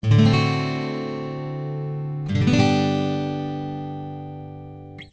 Guitar.ogg